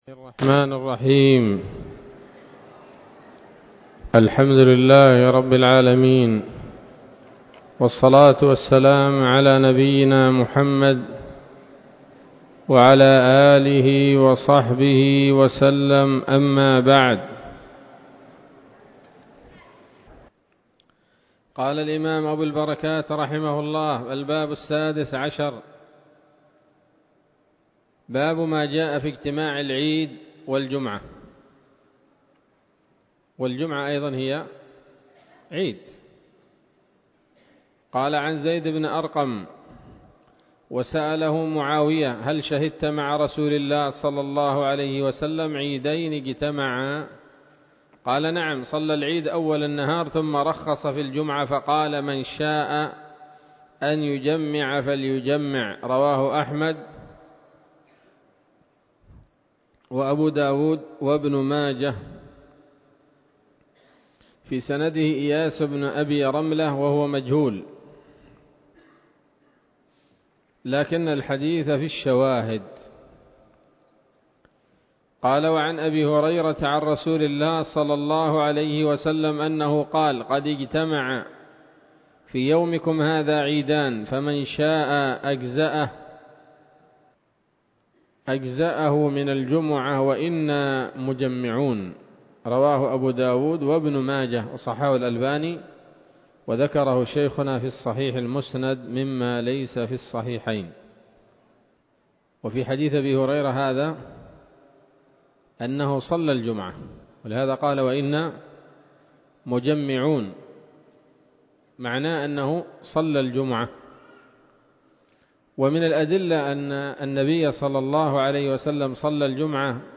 الدرس الخامس والثلاثون وهو الأخير من ‌‌‌‌أَبْوَاب الجمعة من نيل الأوطار